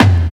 108 TOM LO-L.wav